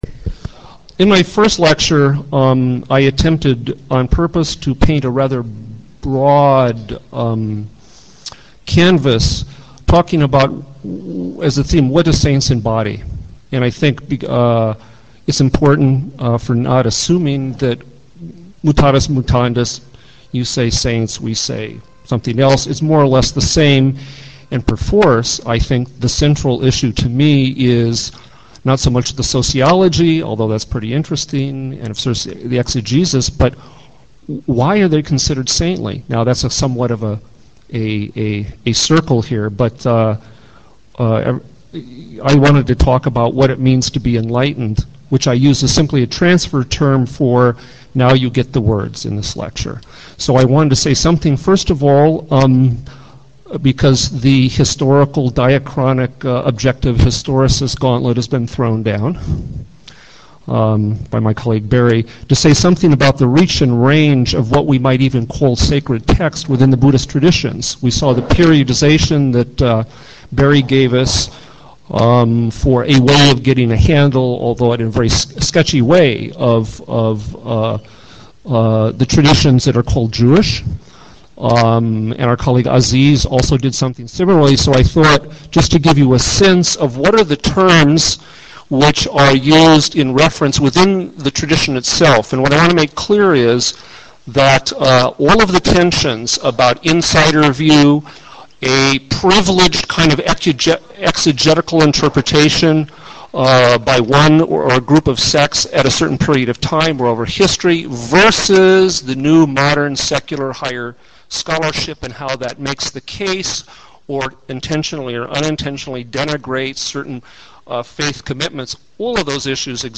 Audio Lectures on topics like interfaith alliance,interfaith christian,interfaith community,interfaith council,interfaith jewish